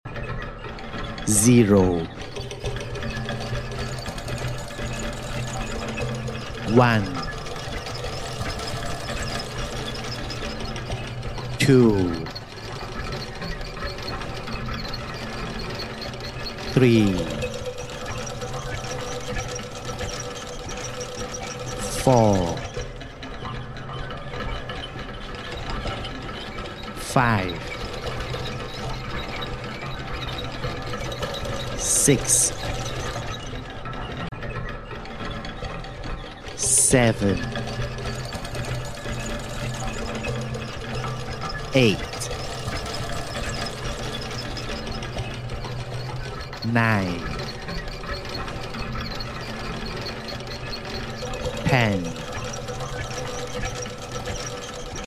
Metal numbers with gears mechanism